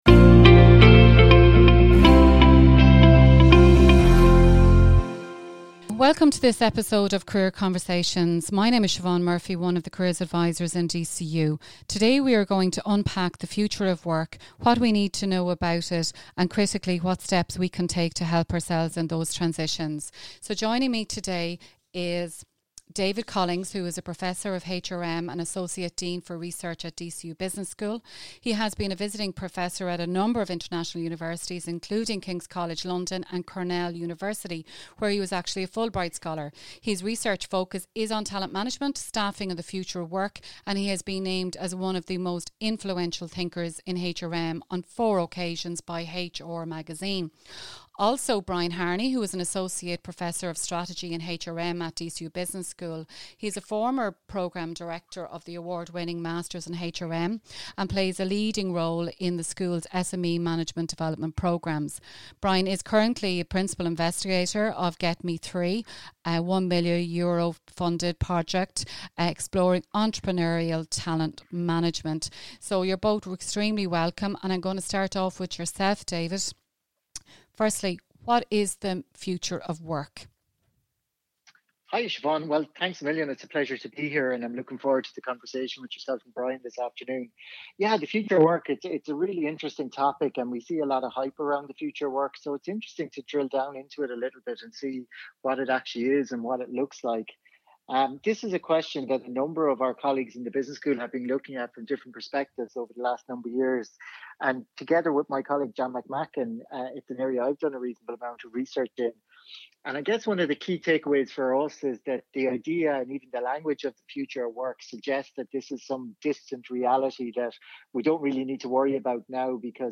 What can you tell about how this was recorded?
This episode was recorded remotely on 29th of April 2020.